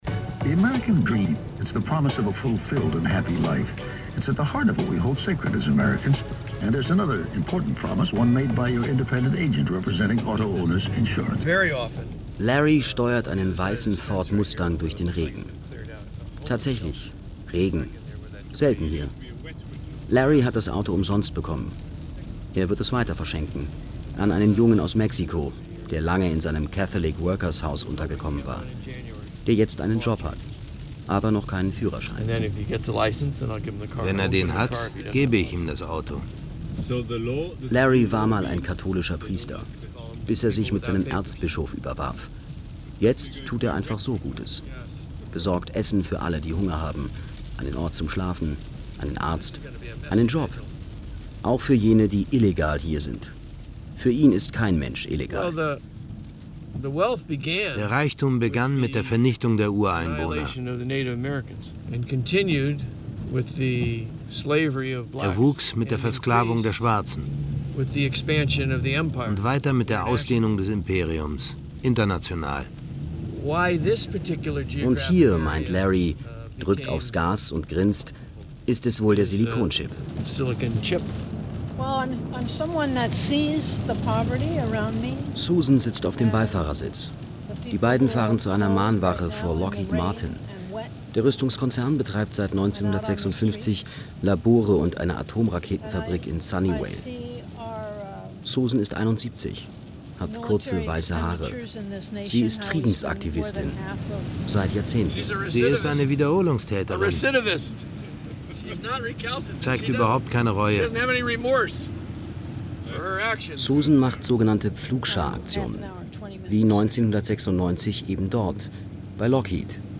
Durch raffiniert eigesetzten Sound fasziniert und befremdet der Silicon Blues, macht traurig, ratlos, zornig und er rüttelt auf.